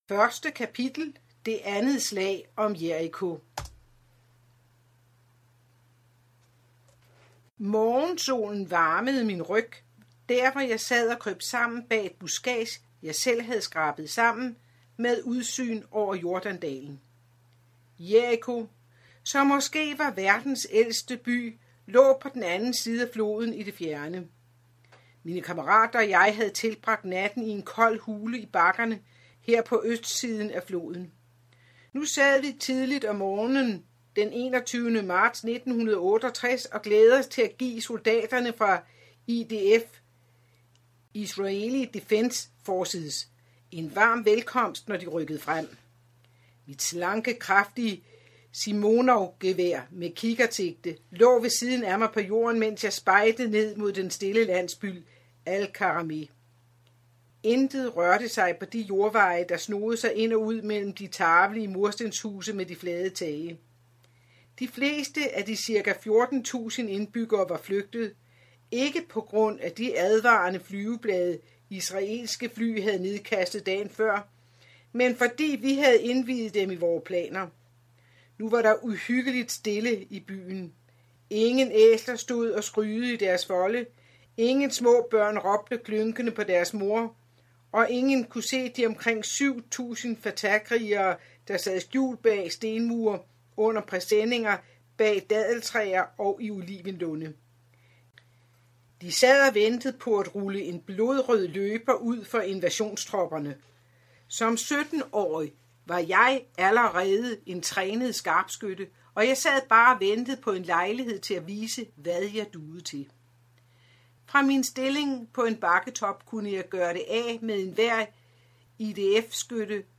Hør et uddrag af Jeg var snigskytte Jeg var snigskytte for Arafat Historien om en snigskyttes omvendelse Format MP3 Forfatter Tass Saada og Dean Merrill Bog Lydbog E-bog 99,95 kr.